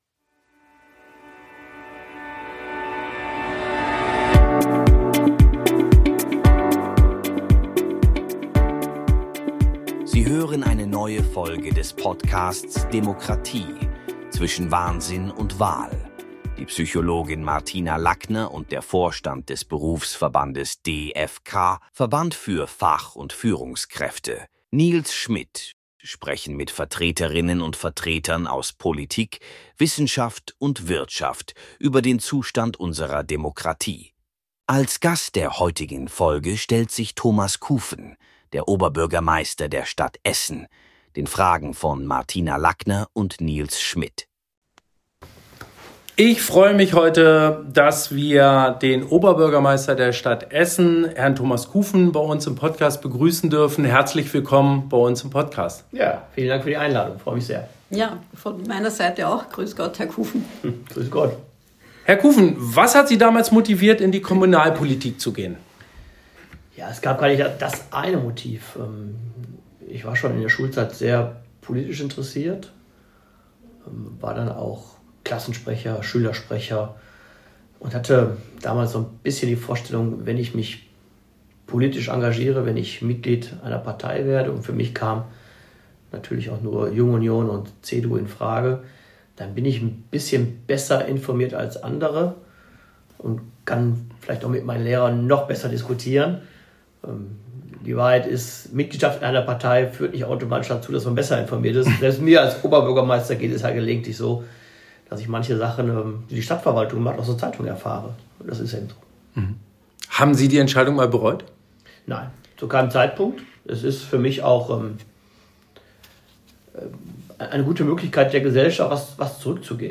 In dieser Folge ist Thomas Kufen zu Gast. Er ist Oberbürgermeister der Stadt Essen und spricht mit uns über seinen Werdegang zum Politiker, die Kritik aus der Gesellschaft und auf welche Dinge er in seinem Amt besonders Wert legt.